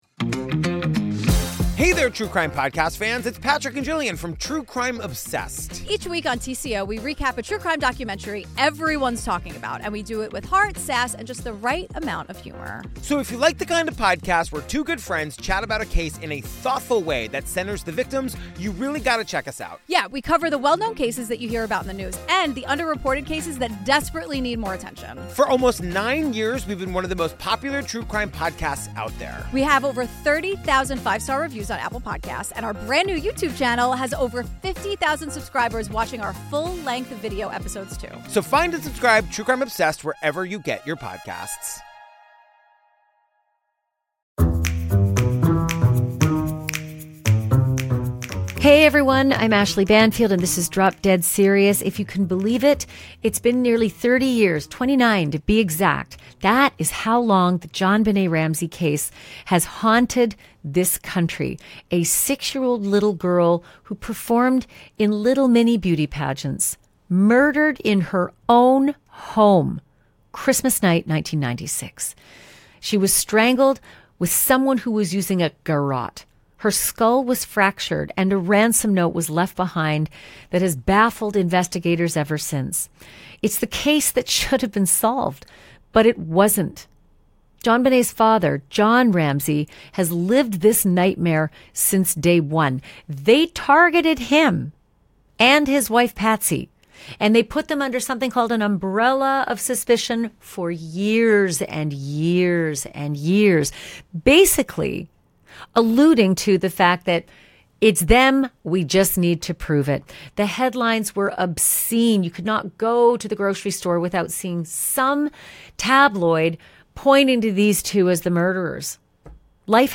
Nearly three decades after the shocking murder of six-year-old JonBenet Ramsey, new attention is being put on the evidence that has never been fully tested. JonBenet’s father, John Ramsey, sits down for an exclusive interview about the garrote used to strangle his daughter and...